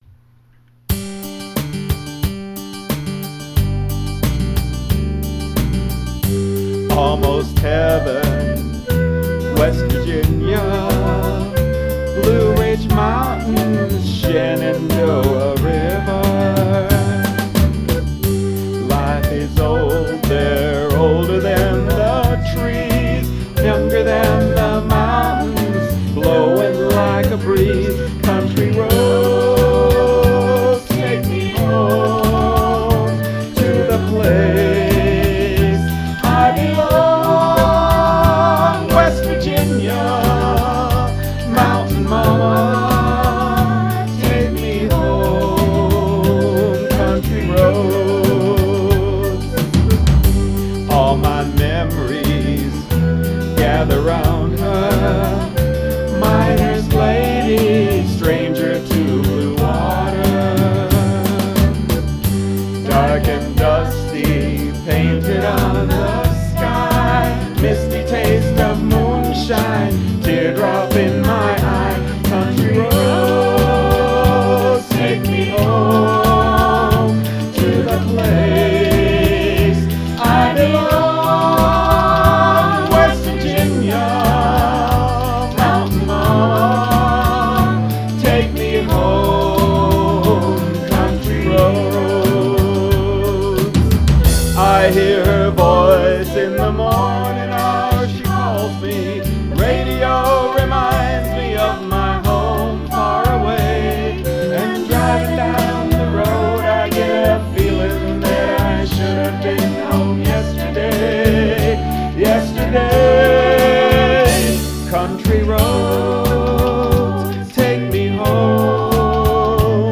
2:42 - k:C,C#